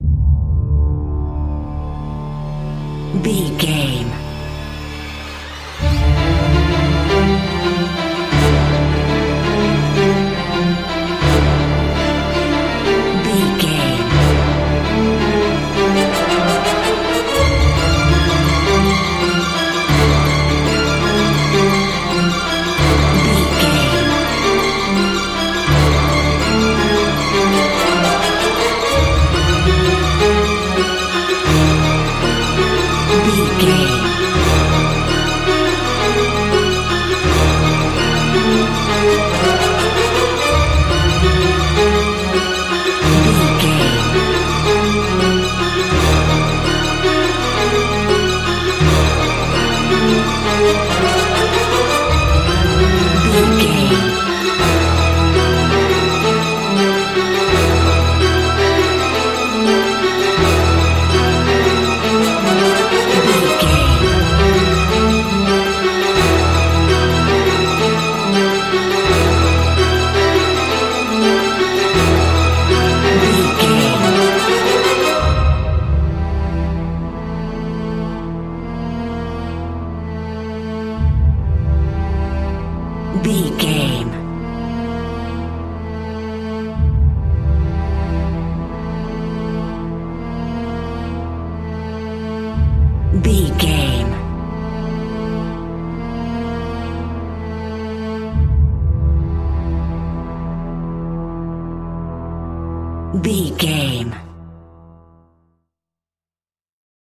Old School Retro Horror Music.
In-crescendo
Aeolian/Minor
D
scary
ominous
dark
suspense
eerie
strings
brass
orchestra
synth
pads